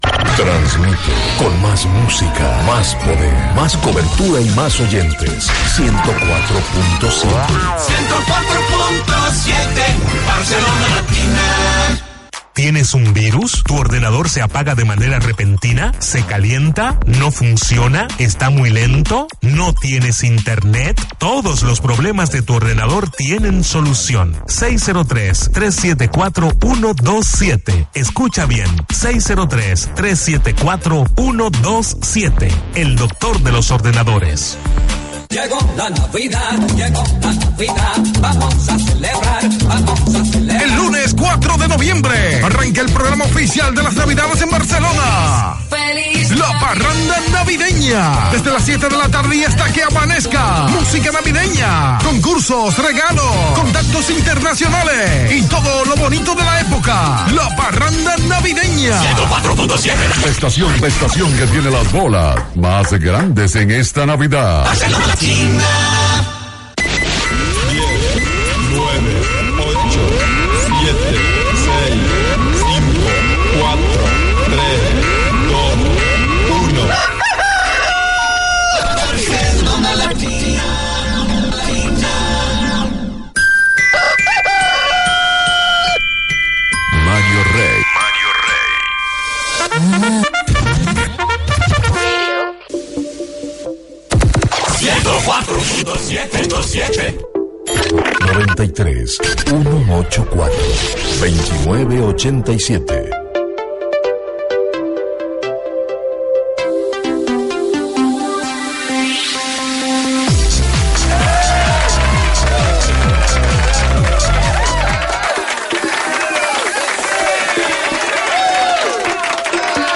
Indicatiu de l'emissora, publicitat, promoció de "La parranda navideña", careta del programa, hora, identificació, telèfon de participació, estat del temps, indicatiu, hora, salutacions diverses, formes com es pot escoltar el programa, hora, indicatiu del programa i tema musical
Entreteniment
FM